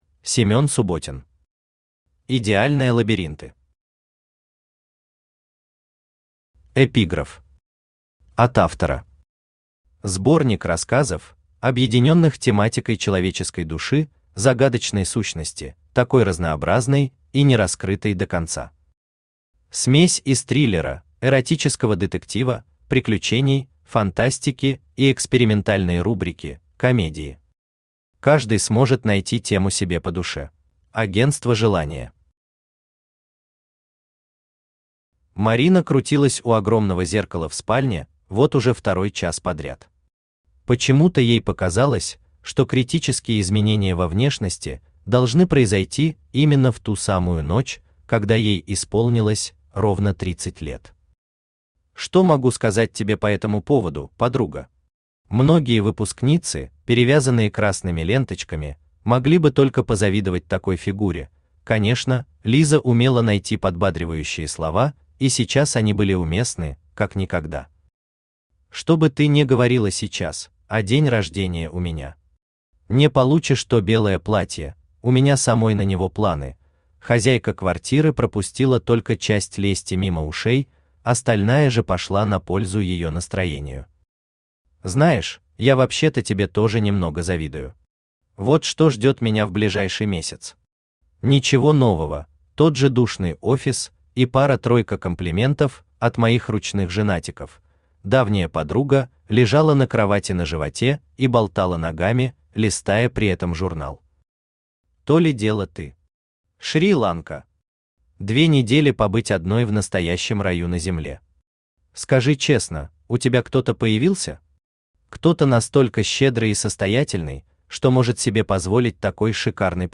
Aудиокнига Идеальные лабиринты Автор Семён Субботин Читает аудиокнигу Авточтец ЛитРес.